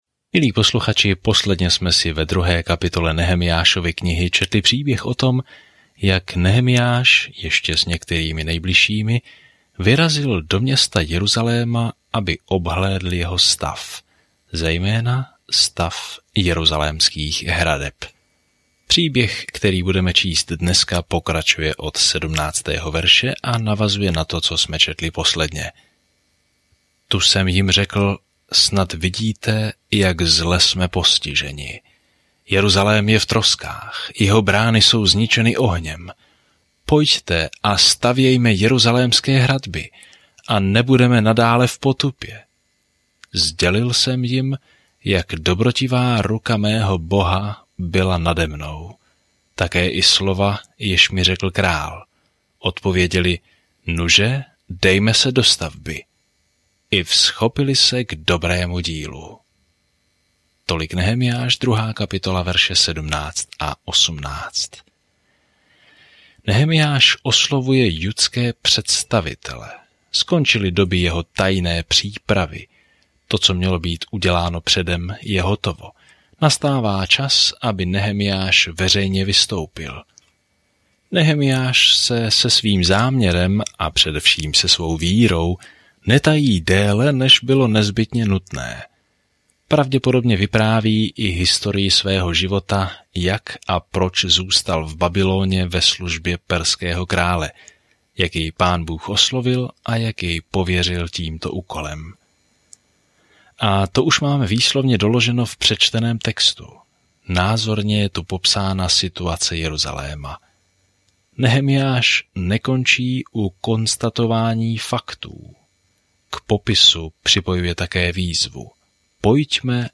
Písmo Nehemiáš 2:17-20 Nehemiáš 3:1-4 Den 3 Začít tento plán Den 5 O tomto plánu Když se Izrael vrátí do své země, Jeruzalém je ve špatném stavu; obyčejný muž, Nehemiah, v této poslední historické knize znovu staví zeď kolem města. Denně procházejte Nehemiášem a poslouchejte audiostudii a čtěte vybrané verše z Božího slova.